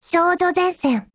贡献 ） 协议：Copyright，其他分类： 分类:少女前线:史蒂文斯520 、 分类:语音 您不可以覆盖此文件。
Stevens520_TITLECALL_JP.wav